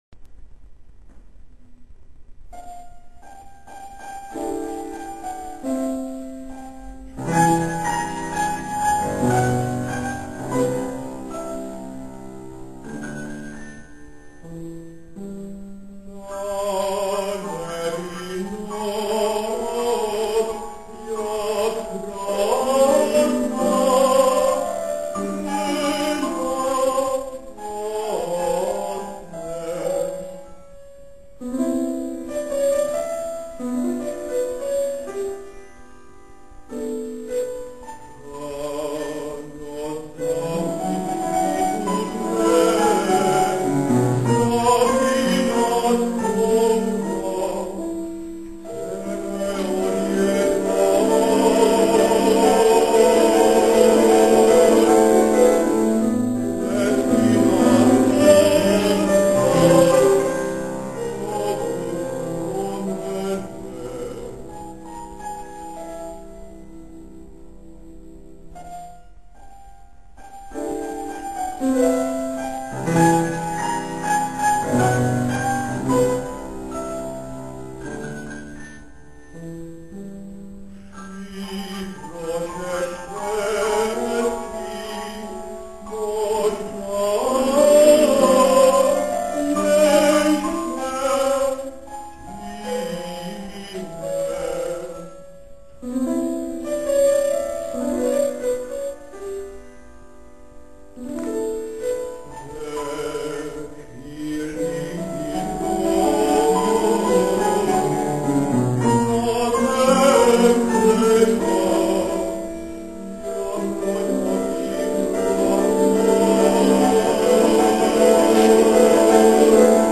27. komorní koncert na radnici v Modřicích
Ukázkové amatérské nahrávky WMA:
Z cyklu Zahrada stínů, zpěv
klavír